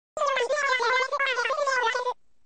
animal_crossing_bla.mp3